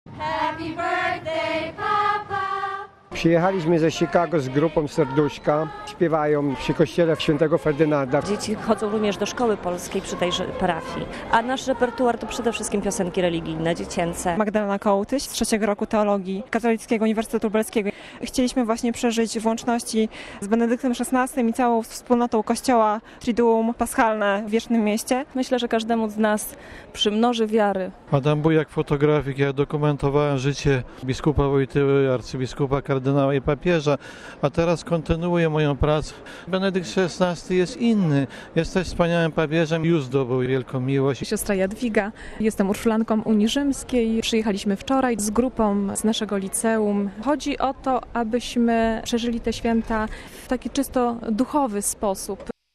Przy pięknej wiosennej aurze Benedykt XVI spotkał się z wiernymi na Placu św. Piotra.
Jeden z zespołów o 4 dni antycypował papieskie urodziny. Słuchaj „Sto lat” i wypowiedzi pielgrzymów: RealAudio